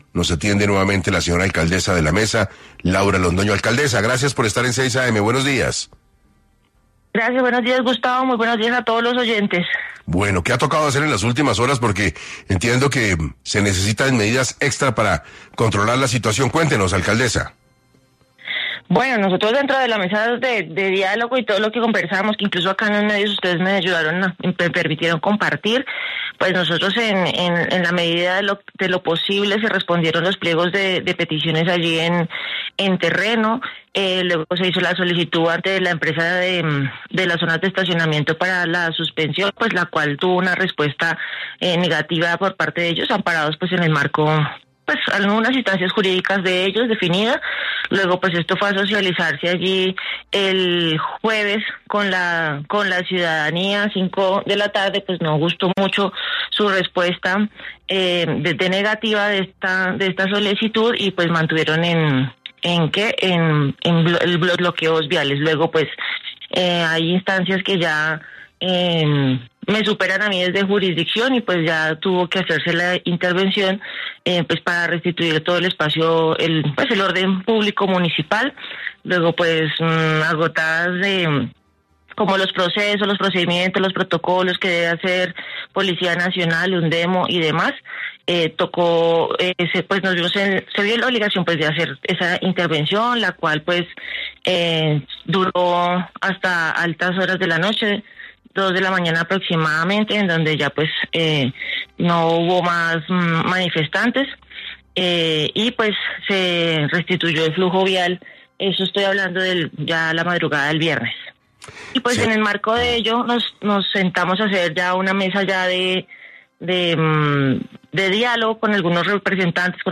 Laura Londoño explicó en 6AM las medidas adoptadas para mantener el orden público ante las constantes manifestaciones que exigen cambios en el sistema de parqueo y que han generado malestar entre los comerciantes.